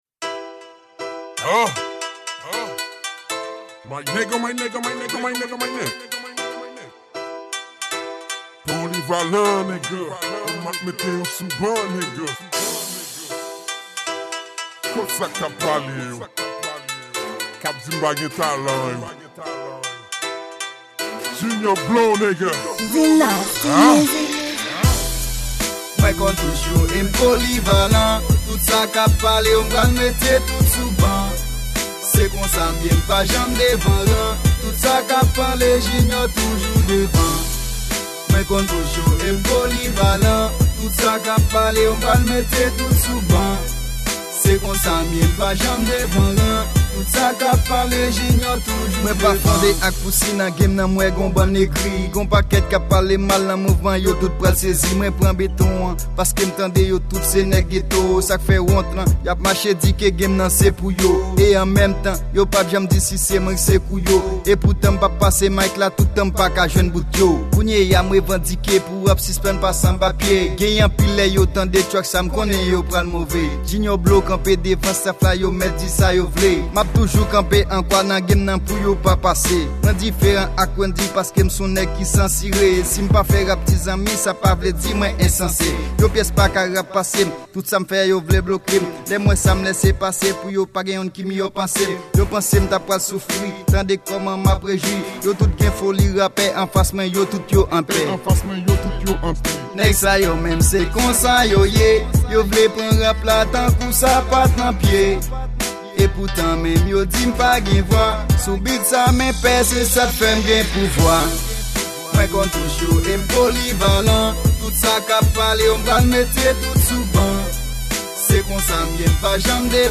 Genre:Rap